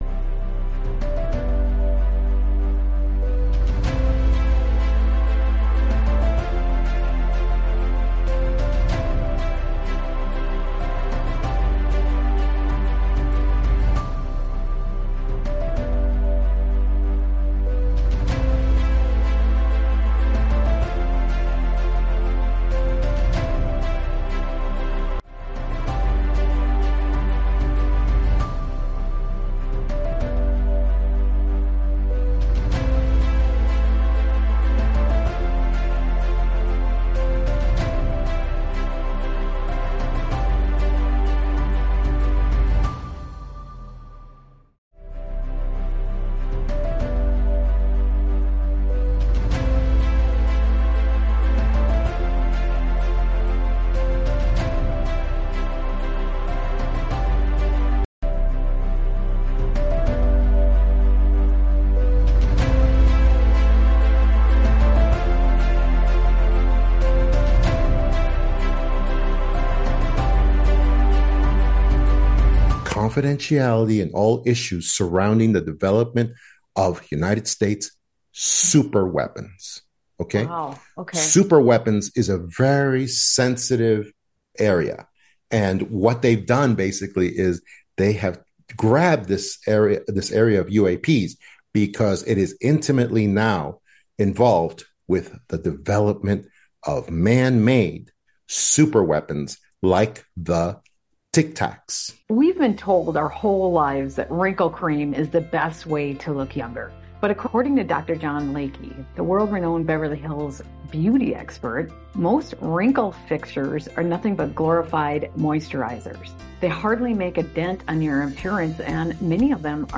➡ The speaker, an FBI agent, discusses his experiences with cases that were used as inspiration for TV shows and movies, without his knowledge or consent.